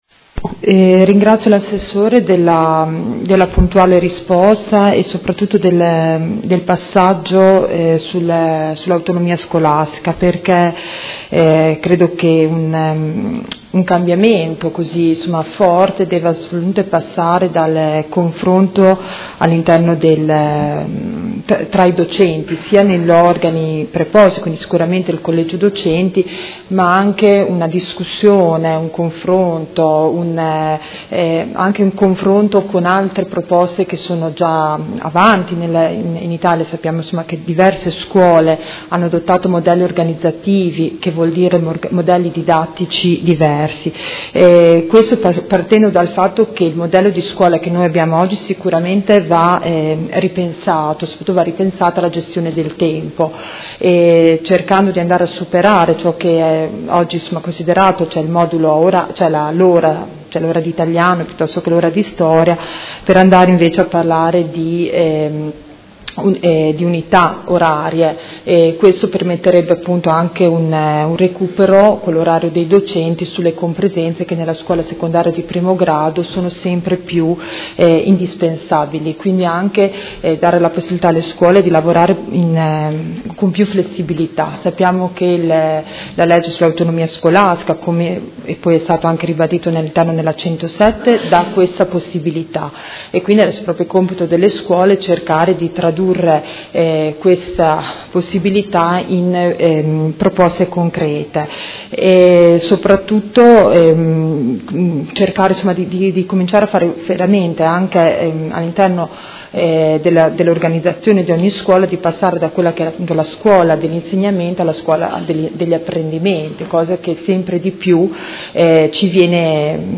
Seduta del 14/12/2017 Replica a risposta. Interrogazione dei Consiglieri Baracchi e Carpentieri (PD) avente per oggetto: Organizzazione oraria scuole secondarie di primo grado